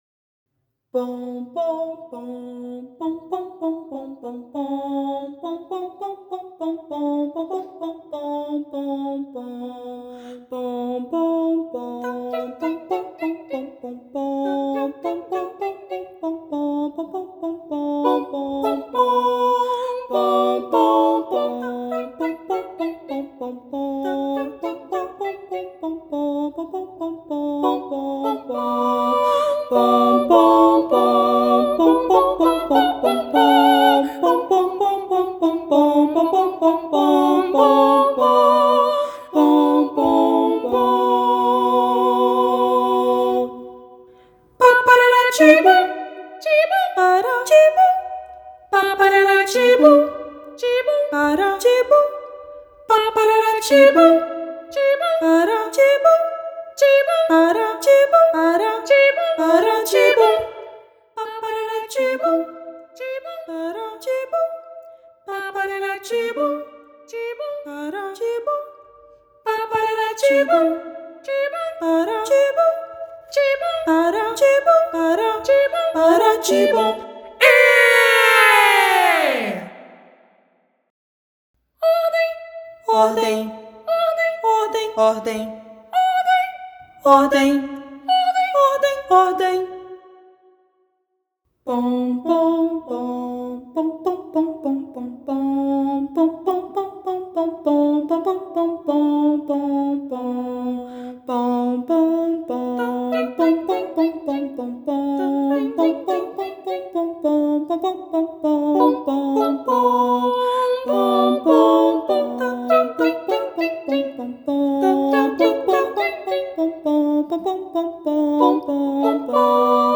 “3 Canções para os pequeninos” é um conjunto de três pequenas peças para coro infantil a três vozes.
Na segunda canção (Acalanto), Rafael Bezerra traz uma singela canção de ninar, com texto próprio, que pode ser vista como uma oração de proteção, enquanto na terceira canção (Marcha), podemos sentir o vigor das bandas militares não apenas pela rítmica utilizada como também pelo uso de onomatopeias que nos transportam para esse universo.
Mix